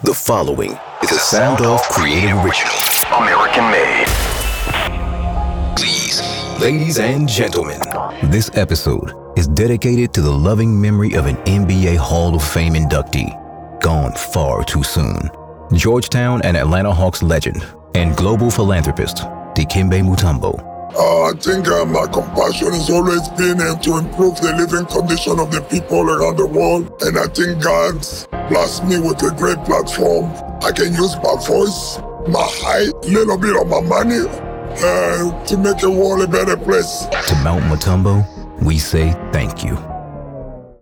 Radiobildgebung
Meine Stimme ist hymnisch, geerdet und unverkennbar echt – eine Mischung aus Textur, Kraft und Seele, die das Publikum berührt und Ihre Botschaft hervorhebt.
Sennheiser 416, Neumann U87, TLM 103